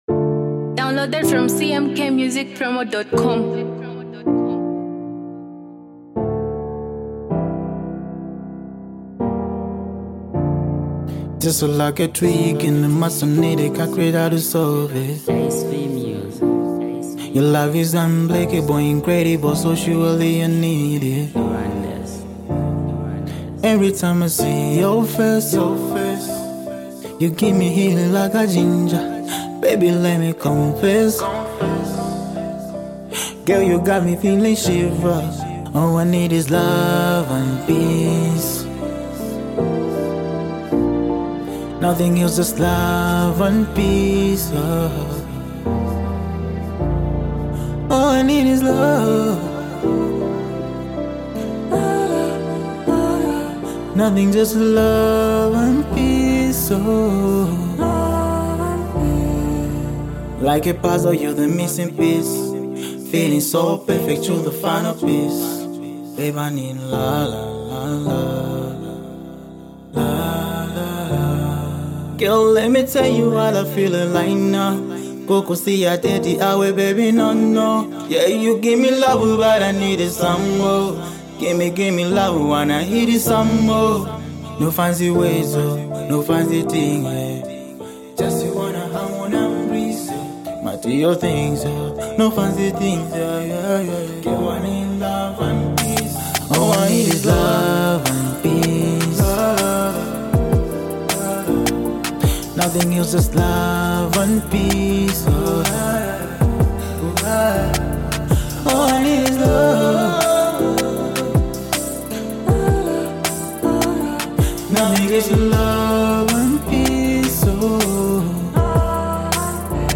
love anthem